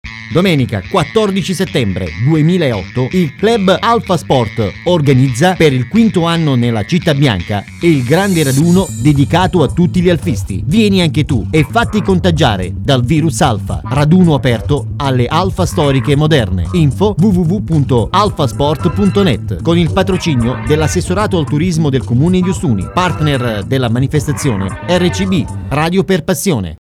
del Raduno su RADIO CITTA' BIANCA